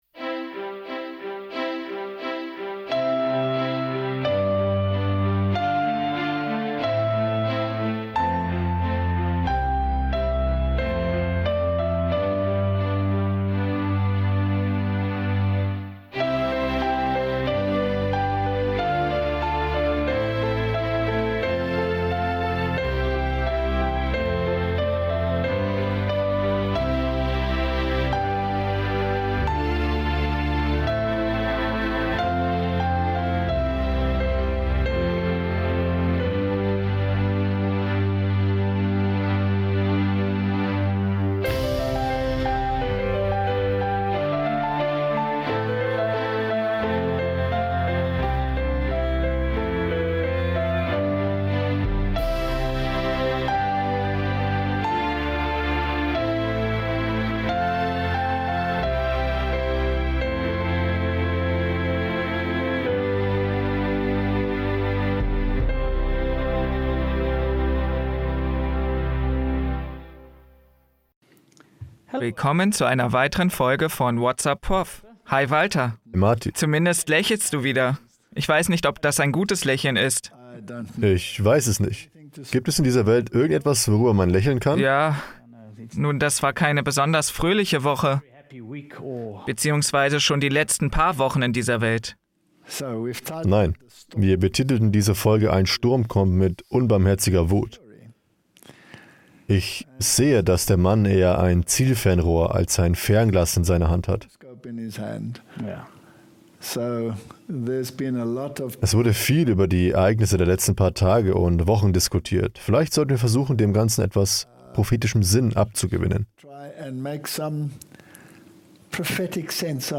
Whats Up, Prof? (Voice Over